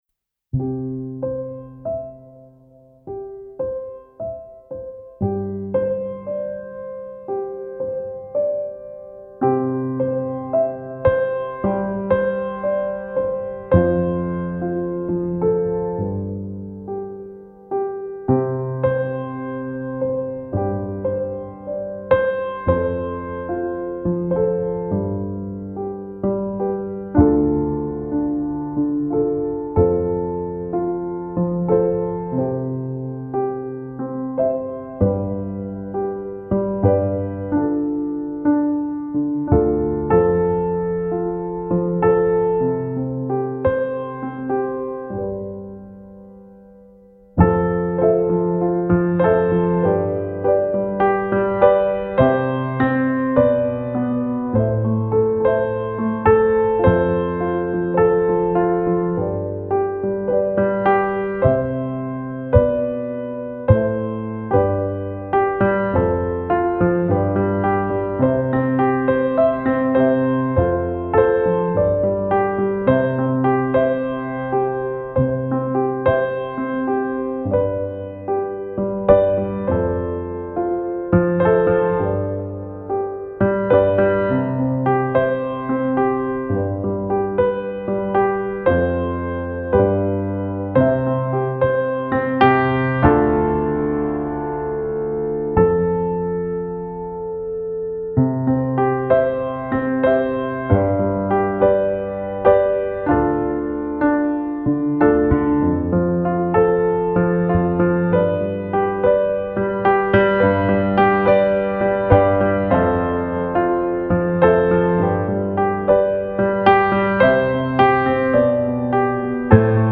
Instrumental version of song 6
intended to be used as a backing track for your performance.